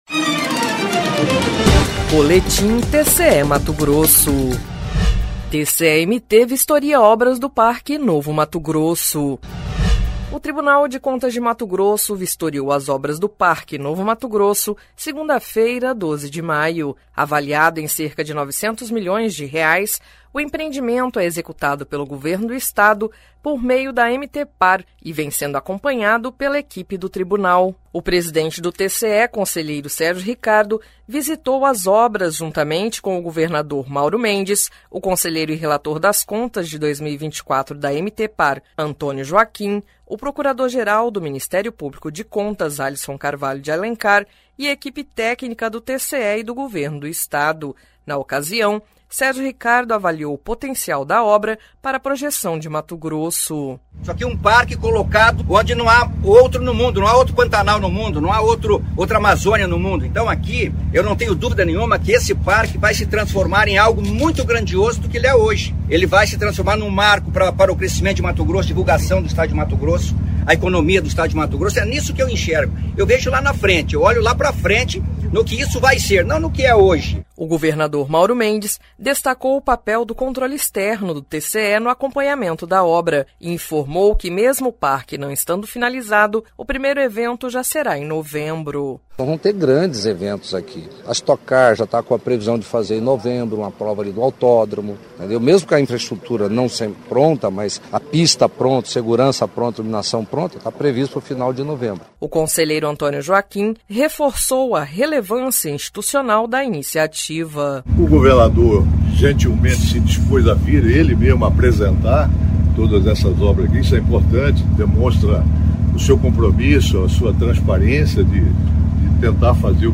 Sonora: Sérgio Ricardo – conselheiro-presidente do TCE-MT
Sonora: Mauro Mendes – governador de MT
Sonora: Antonio Joaquim – conselheiro do TCE-MT
Sonora: Alisson Carvalho de Alencar - procurador-geral de Contas do MPC